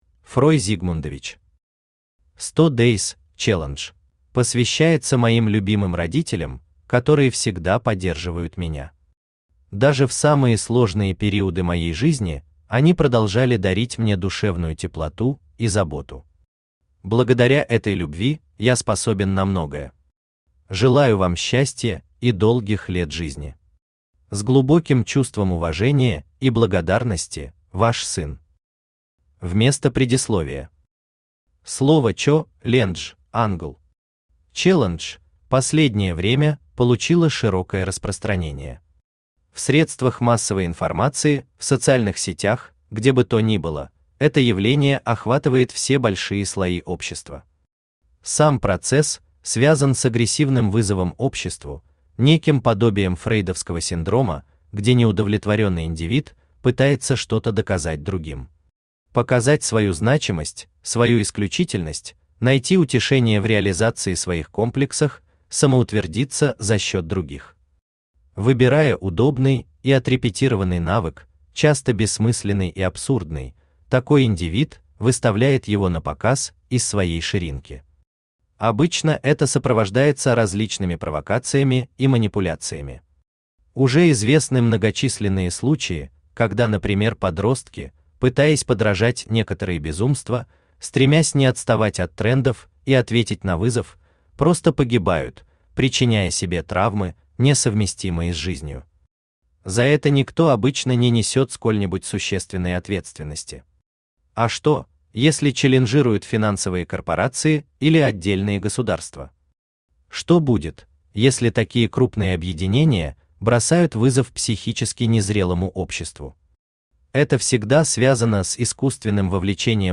Aудиокнига 100 Days Challenge Автор Фрой Зигмундович Читает аудиокнигу Авточтец ЛитРес.